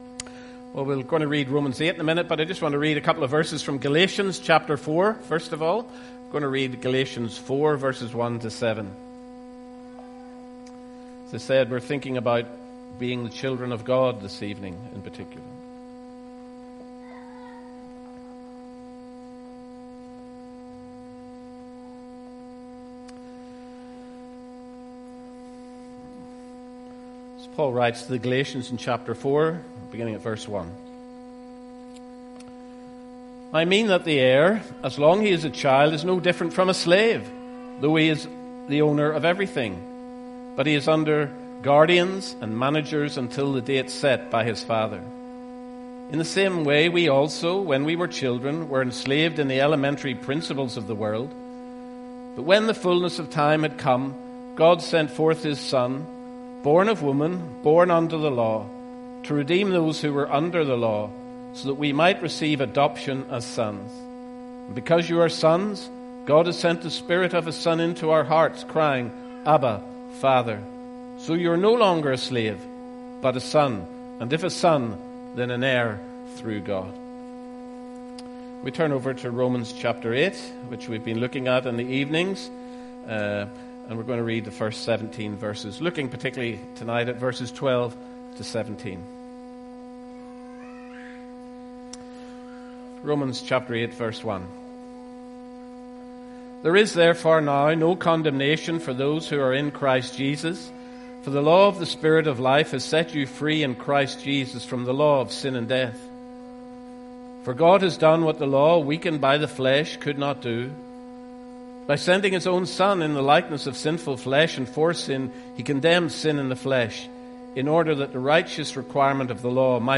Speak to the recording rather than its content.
‹ Close Log In using Email Mar 01, 2026 Romans 8 Assurance #3 – Children of God MP3 SUBSCRIBE on iTunes(Podcast) Notes 1st March 2026 - Evening Service Readings: 1 John 2:28-3:10 Romans 8:1-17